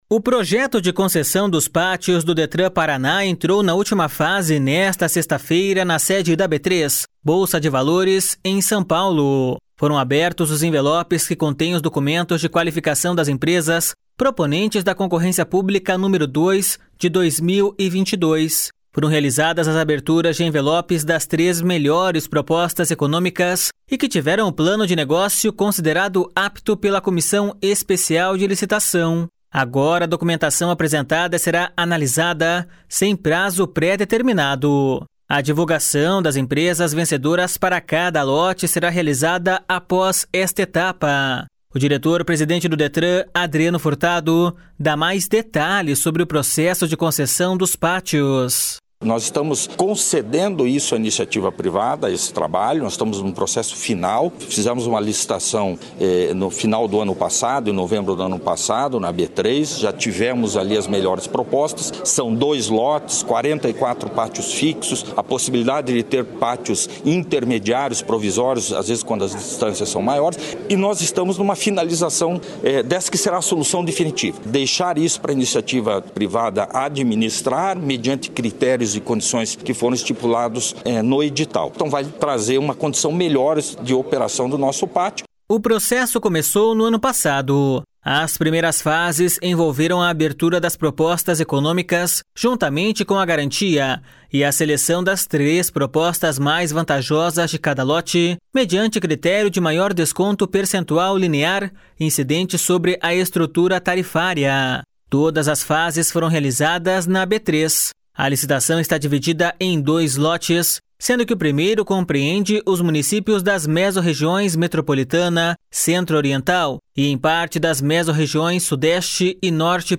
O diretor-presidente do Detran, Adriano Furtado, dá mais detalhes sobre o processo de concessão dos pátios.// SONORA ADRIANO FURTADO.//